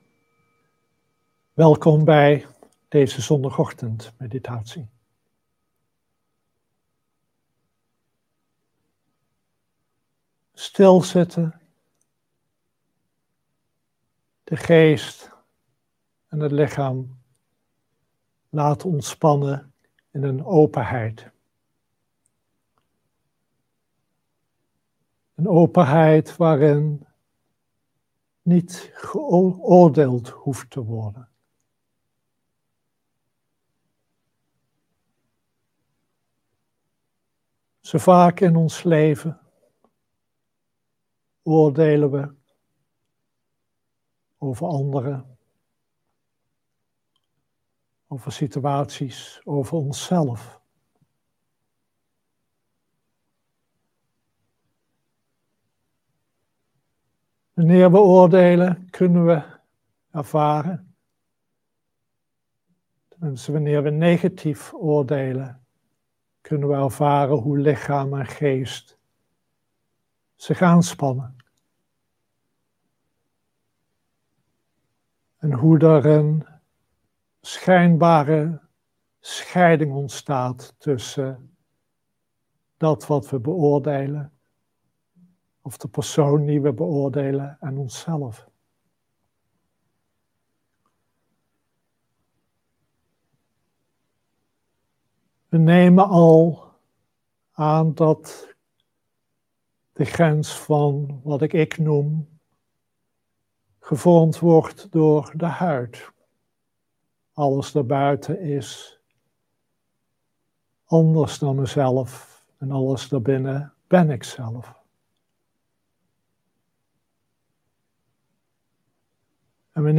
Dharma-onderwijs
Livestream opname